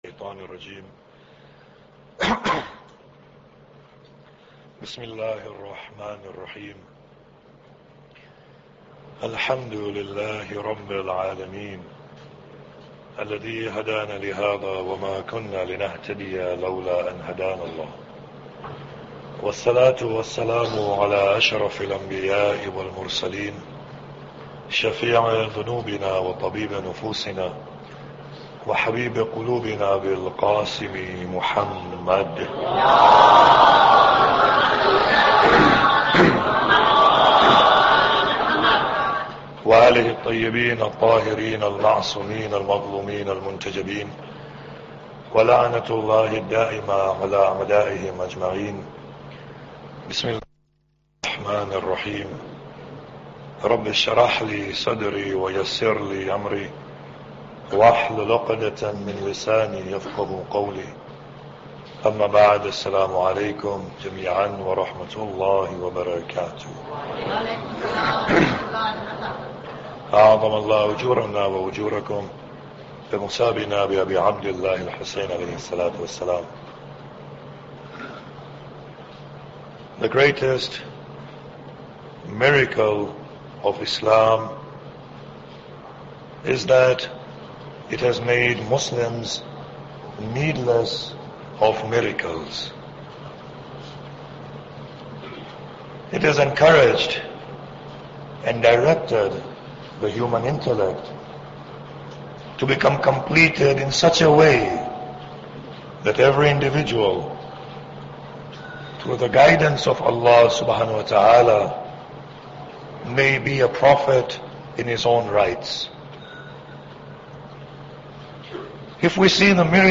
Muharram Lecture 2